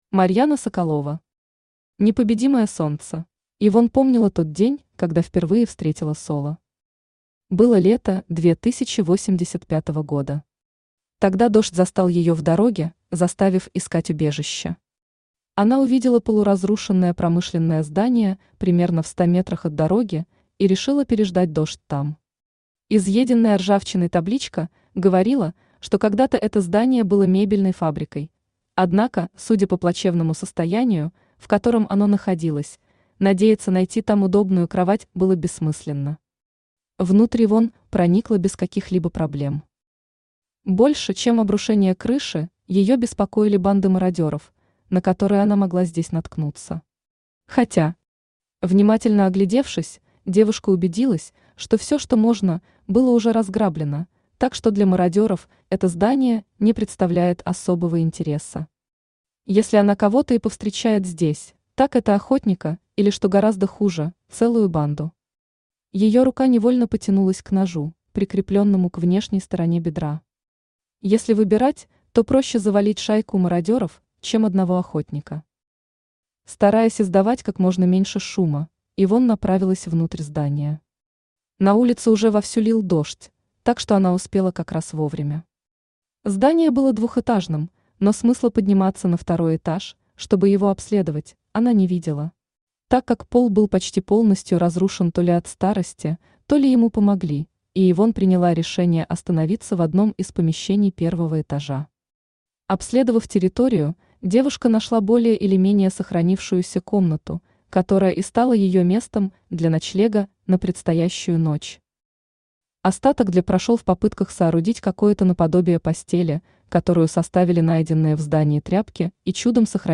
Аудиокнига Непобедимое Солнце | Библиотека аудиокниг
Aудиокнига Непобедимое Солнце Автор Марьяна Соколова Читает аудиокнигу Авточтец ЛитРес.